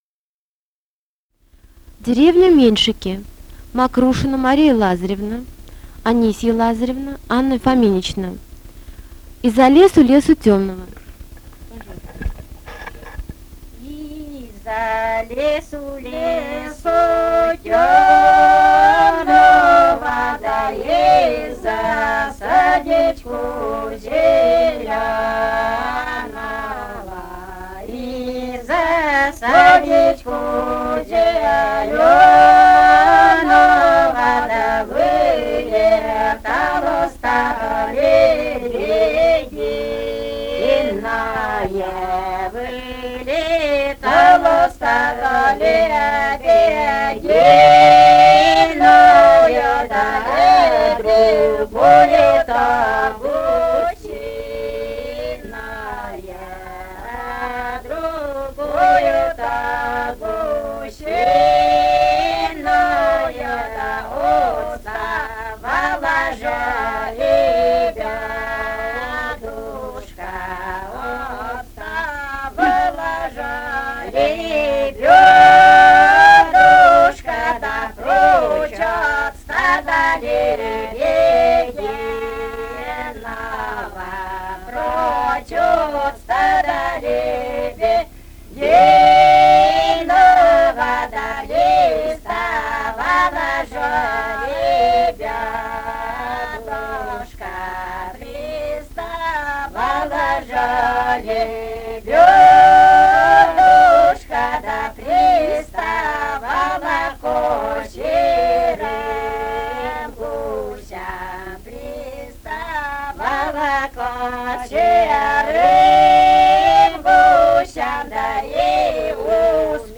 Этномузыкологические исследования и полевые материалы
«Из-за лесу, лесу тёмного» (свадебная).
Пермский край, д. Меньшиково Очёрского района, 1968 г. И1077-25